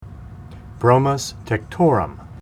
Pronunciation Cal Photos images Google images